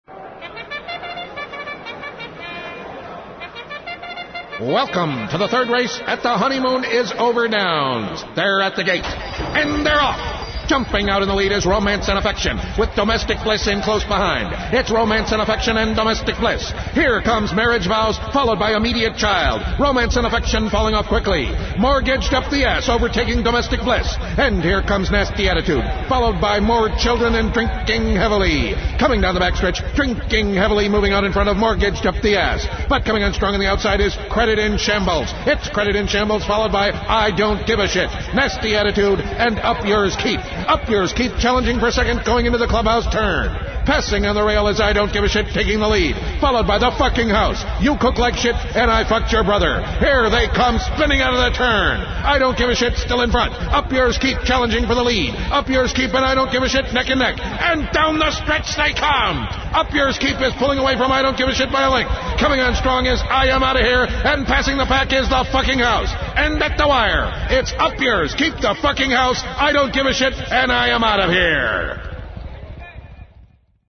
(Click on it) Marital horse race (it takes a while, but it's worth it, need speakers) This is what happens when you stop Hashing with Sir Walter's Hash House Harriers...
HorseRace.mp3